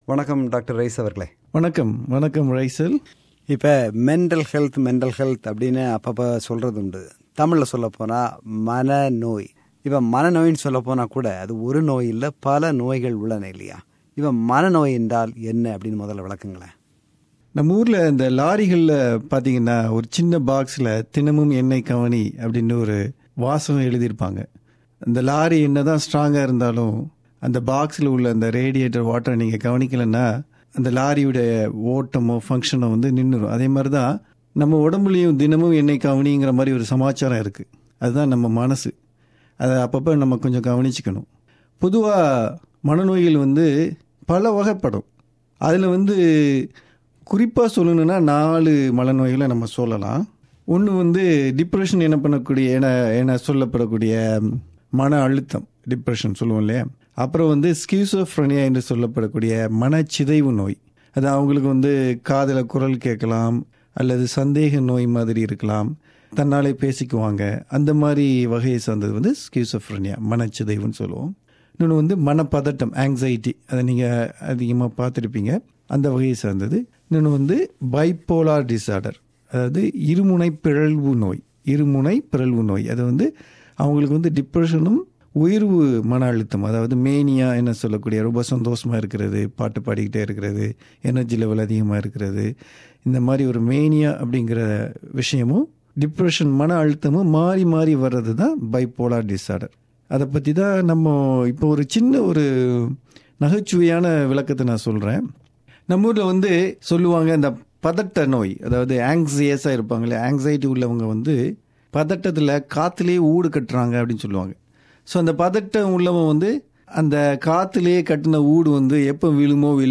எளிய தமிழில் விளக்கமளிக்கிறார் மனநோய் மருத்துவர்